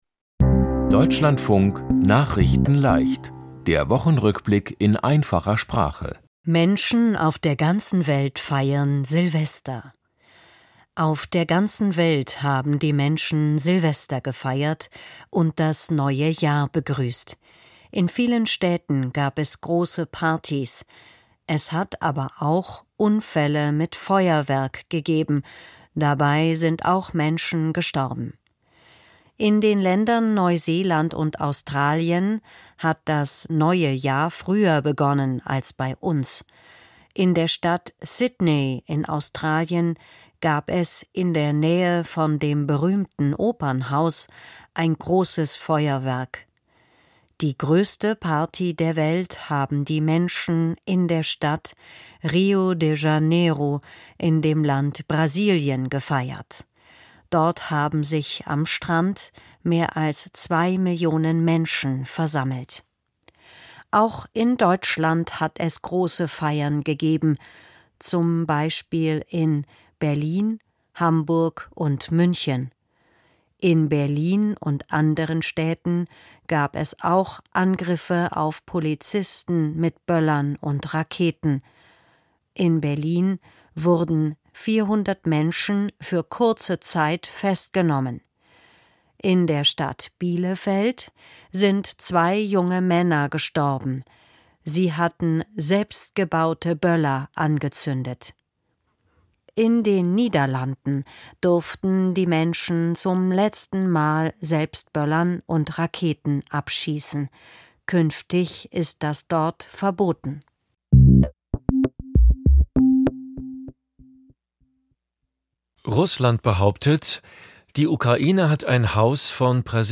Einfacher Sprache
Die Themen diese Woche: Menschen auf der ganzen Welt feiern Silvester, Russland behauptet: Die Ukraine hat ein Haus von Präsident Putin angegriffen, Proteste im Iran wegen Wirtschafts-Krise, „Das Kanu des Manitu“ ist der erfolgreichste Kino-Film des Jahres und Brigitte Bardot mit 91 Jahren gestorben. nachrichtenleicht - der Wochenrückblick in einfacher Sprache.